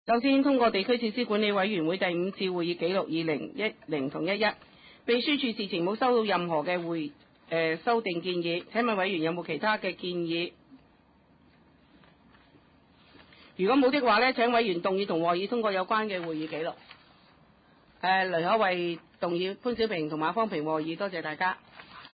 第六次會議(一零/一一)
葵青民政事務處會議室